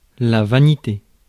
Ääntäminen
France: IPA: [va.ni.te]